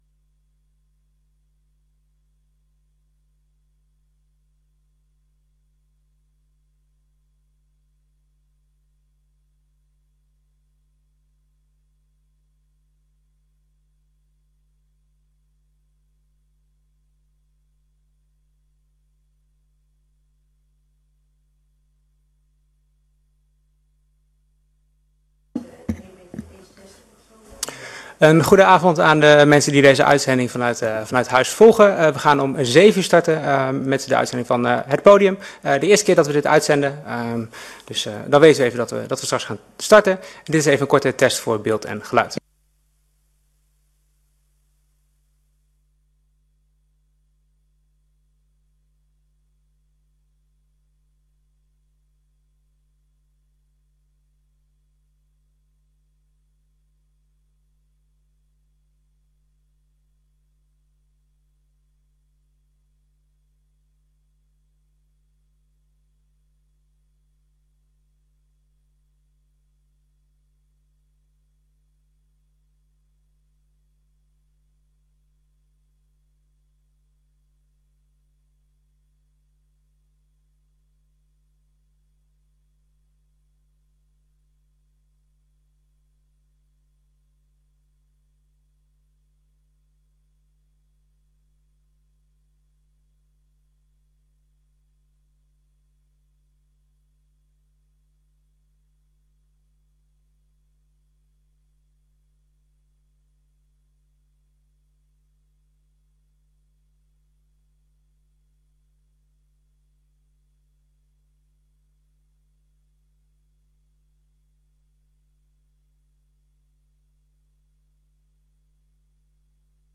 Gemeenteraadsleden mogen in deze tijd ook vragen aan u stellen.